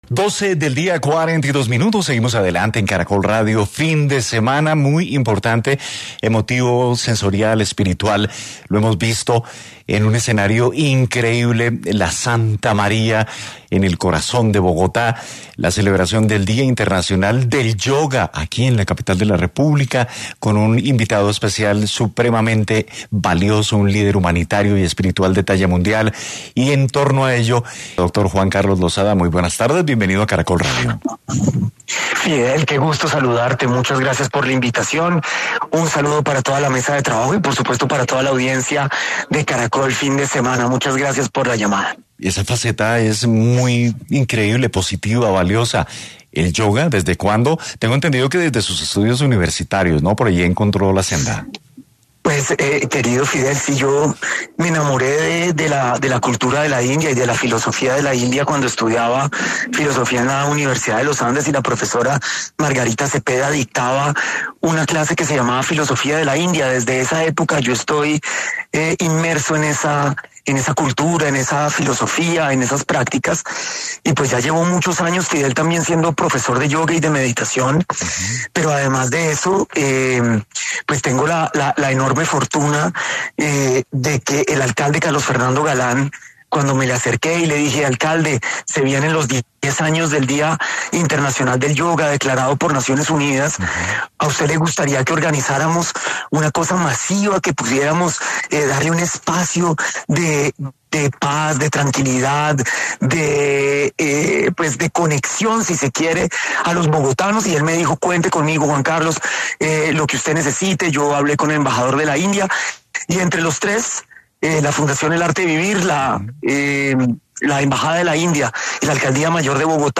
En entrevista con Caracol Radio, el representante a la Cámara en Bogotá, Juan Carlos Losada, explica la importancia de hacer yoga para mejorar la salud mental.